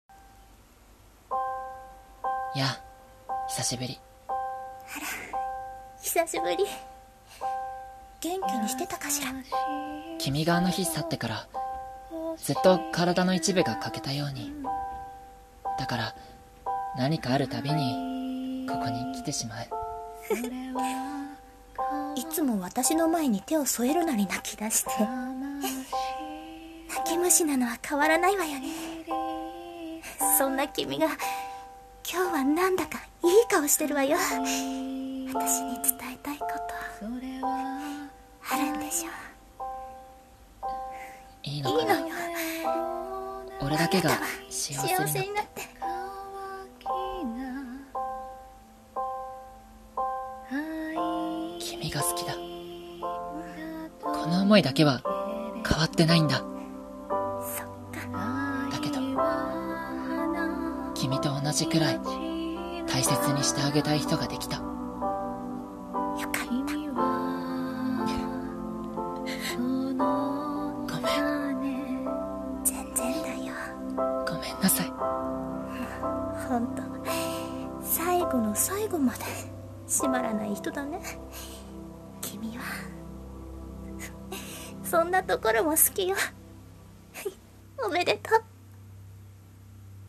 【 声劇 台本 】 天泣